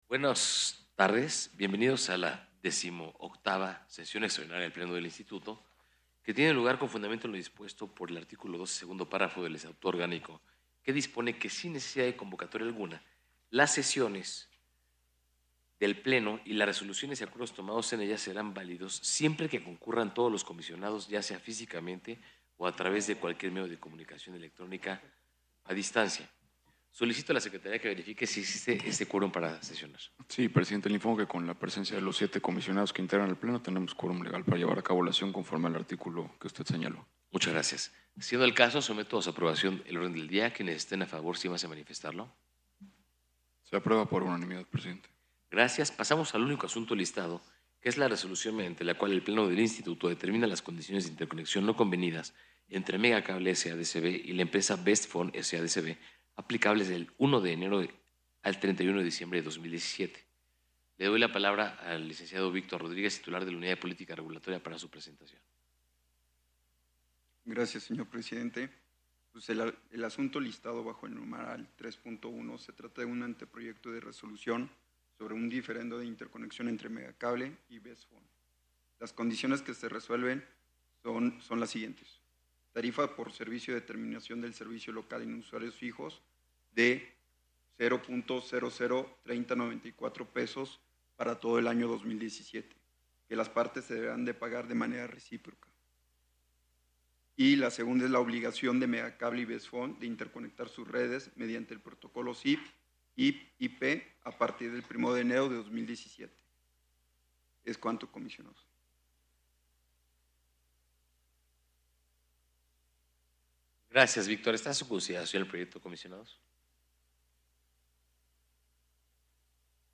XVIII Extraordinaria del Pleno 11 de noviembre de 2016